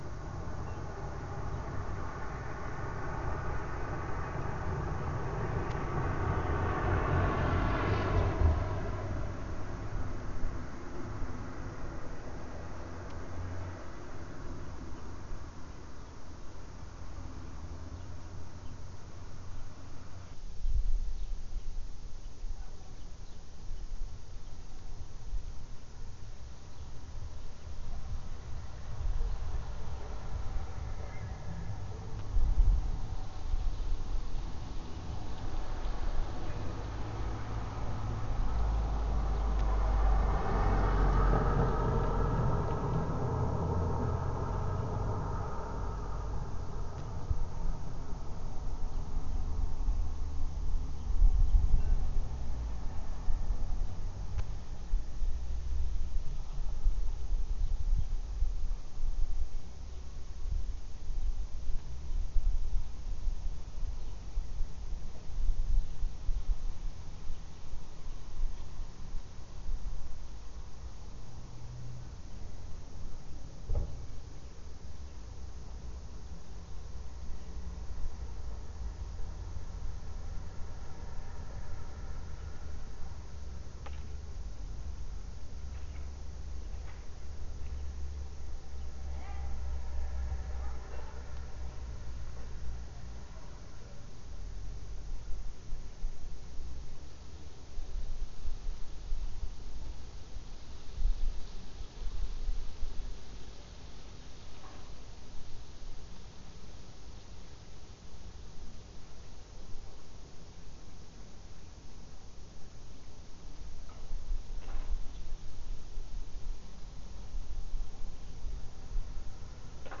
Cette fois j’ai mis le micro à l’extérieur et enregistré les bruits ambiants. La sensibilité est très bonne, on entend des bruits émis par des personnes qui sont à plusieurs dizaines de mètres.